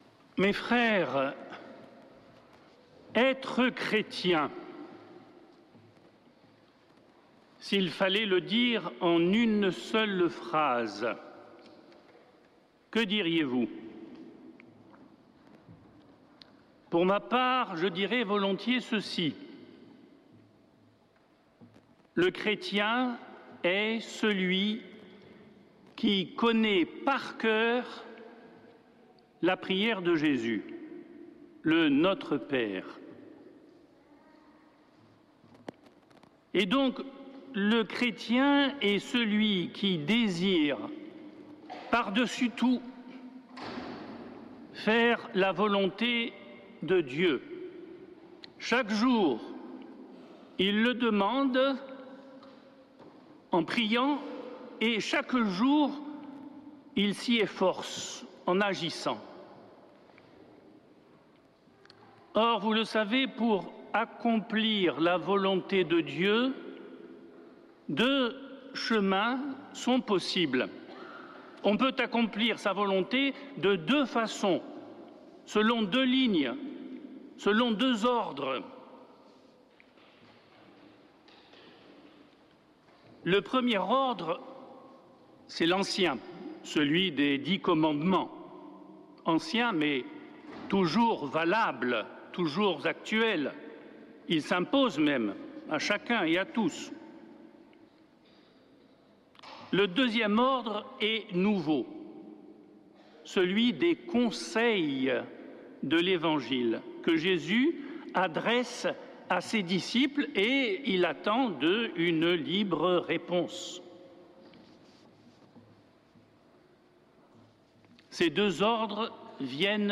Accueil \ Emissions \ Foi \ Prière et Célébration \ Messe depuis le couvent des Dominicains de Toulouse \ Que dois-je faire ?
homelie